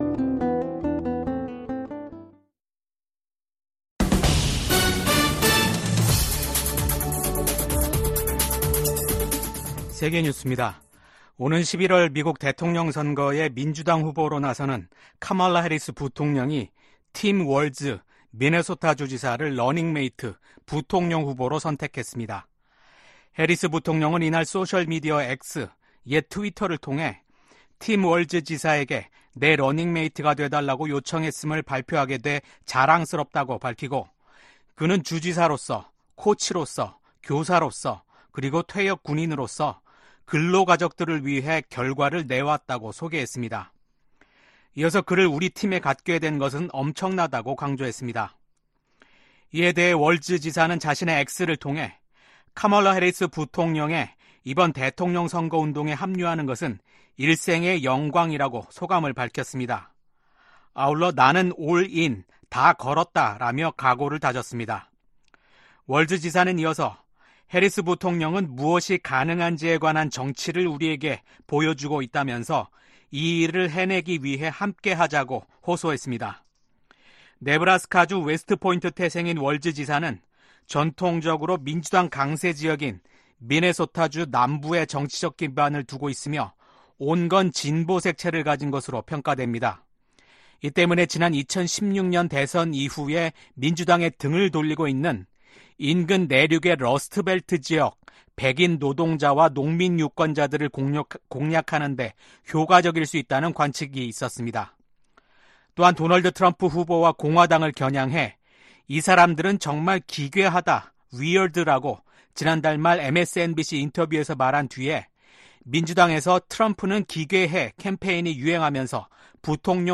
VOA 한국어 아침 뉴스 프로그램 '워싱턴 뉴스 광장' 2024년 8월 7일 방송입니다. 북한이 신형 전술 탄도미사일 발사대를 공개한 것과 관련해 미국의 전문가들은 북한의 점증하는 위협에 맞서 탄도미사일 방어 역량을 강화해야 한다고 지적했습니다. 압록강 유역 수해 복구에 전 사회적 인력 동원에 나선 북한이 외부 지원 제의는 일절 거부하고 있습니다.